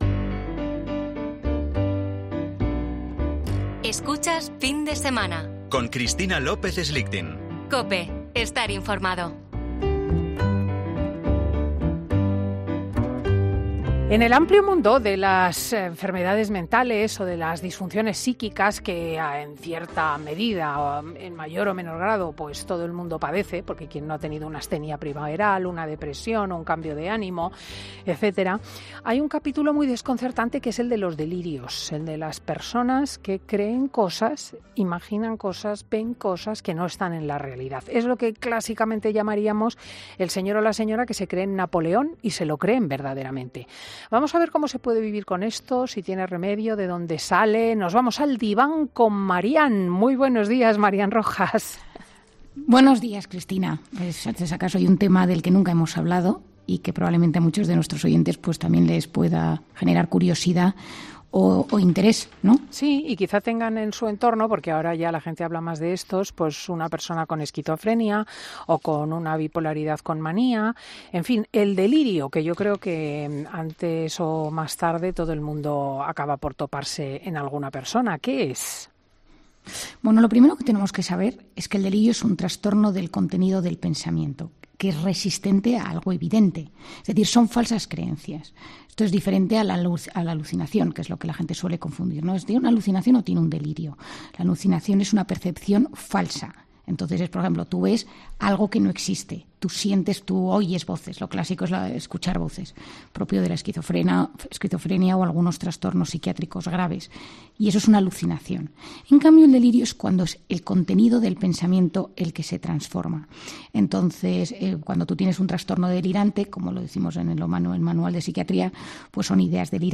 La psiquiatra de cabecera de Fin de Semana aborda con Cristina López Schlichting qué es el delirio, cómo se desarrolla y sus posibles tratamientos